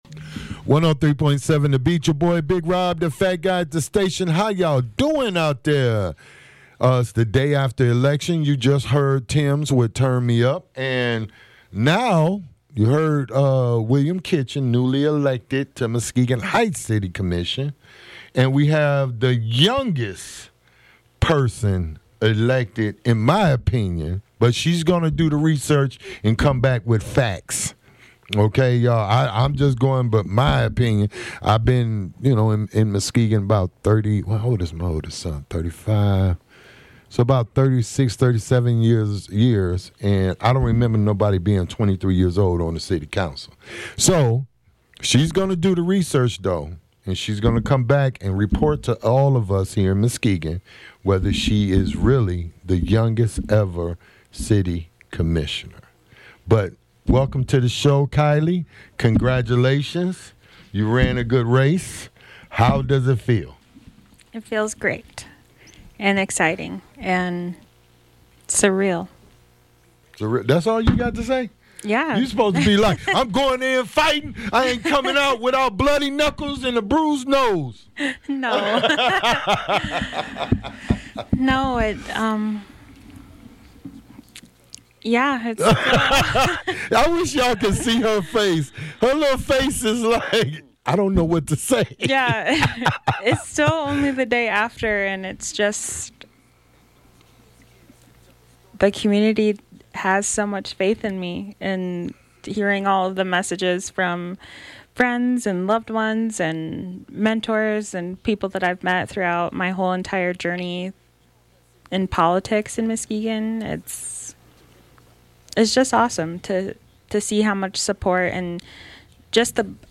Interview with Kiley Jackson City of Muskegon At-Large Commissioner